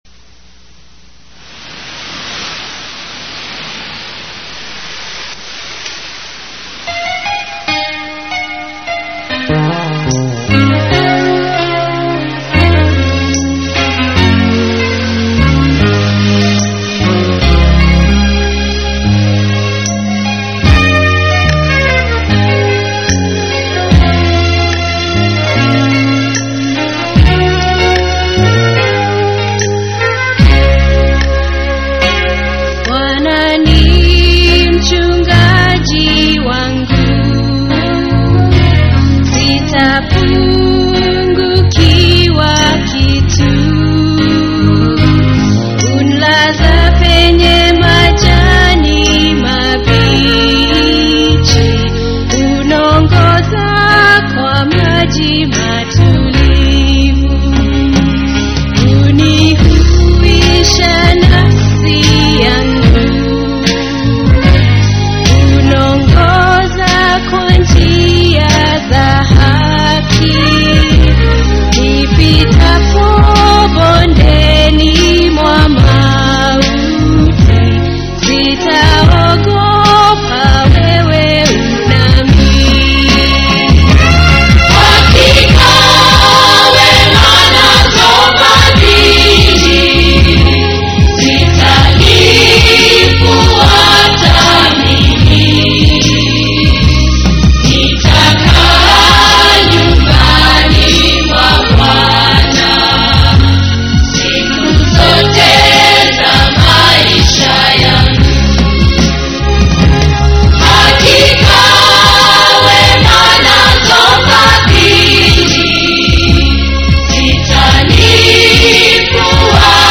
Swahili Songs
This music is all Christian music.